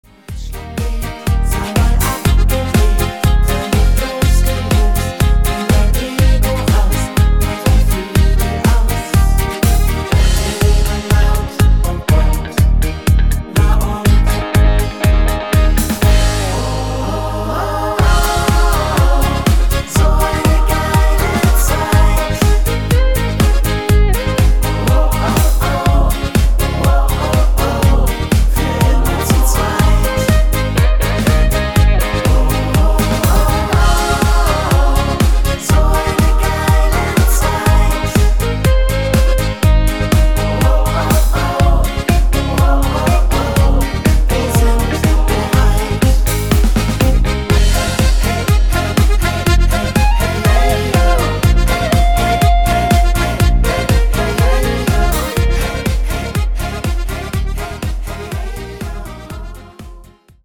fetztiges Lied aus Tirol